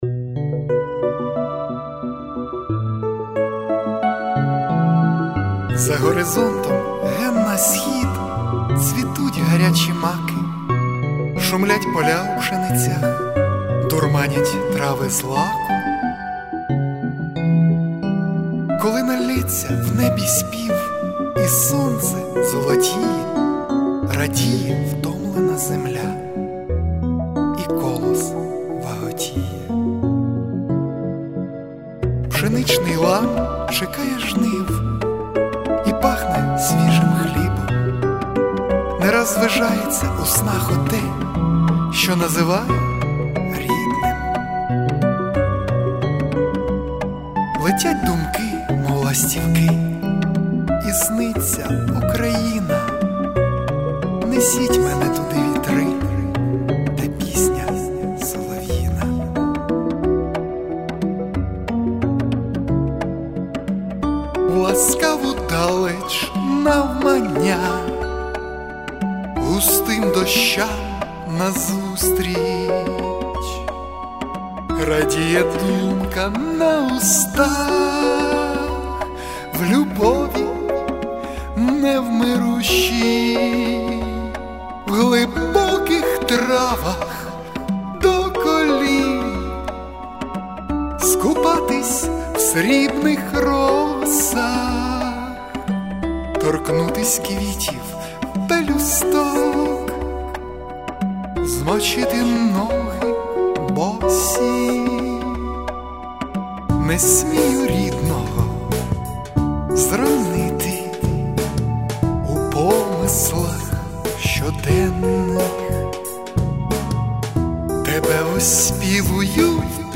Пісня та музичний кліп